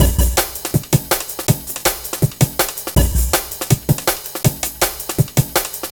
Dinky Break 08-162.wav